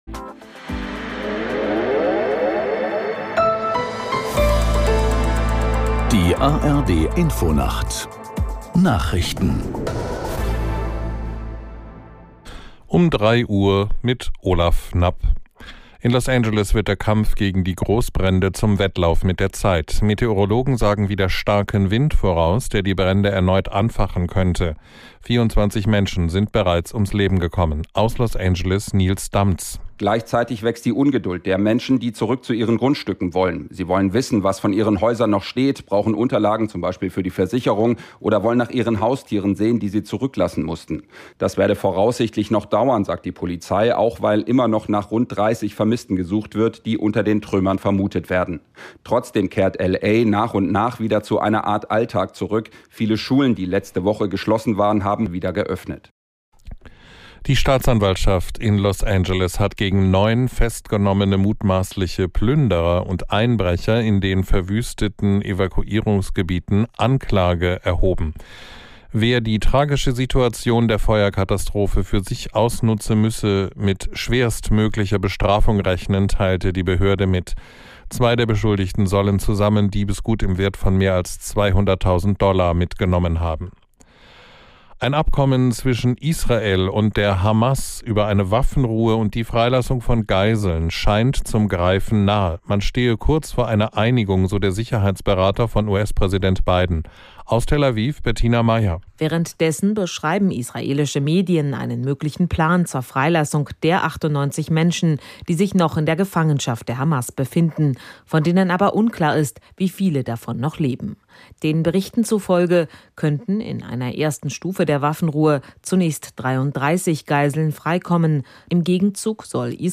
Nachrichten für den Norden. Alle 30 Minuten die aktuellen Meldungen aus der NDR Info Nachrichtenredaktion. Politik, Wirtschaft, Sport. 24 Stunden am Tag - 365 Tage im Jahr.